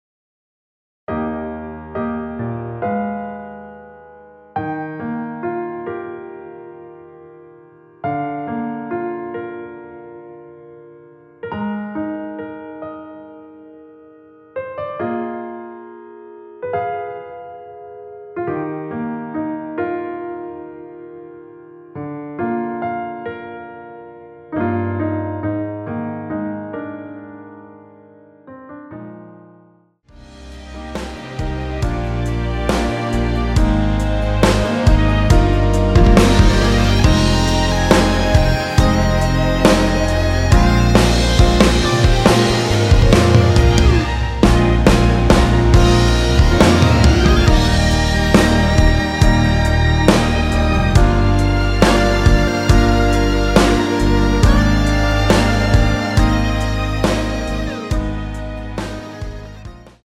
노래 들어가기 쉽게 전주 1마디 만들어 놓았습니다.(미리듣기 확인)
원키에서(-1)내린 MR입니다.
Eb
앞부분30초, 뒷부분30초씩 편집해서 올려 드리고 있습니다.
중간에 음이 끈어지고 다시 나오는 이유는